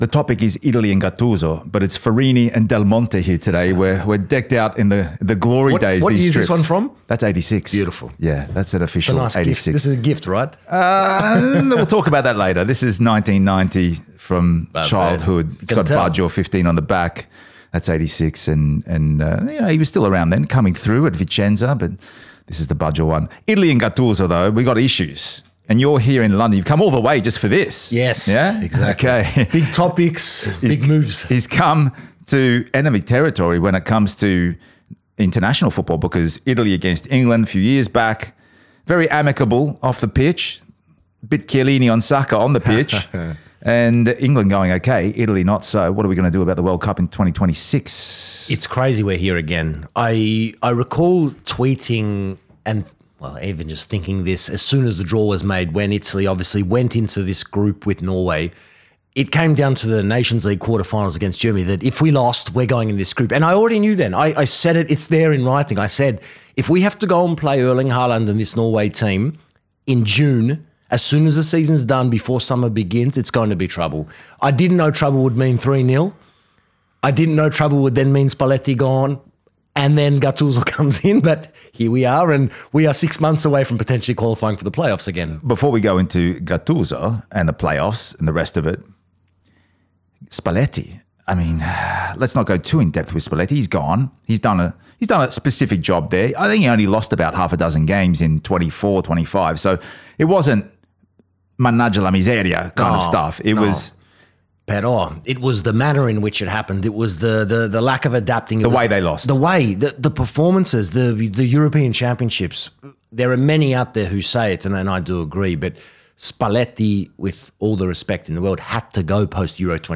The conversation delves into the challenges facing the team, including a lack of depth, cultural shifts in youth engagement with football, and the need for a return to passion in the sport.